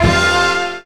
JAZZ STAB 16.wav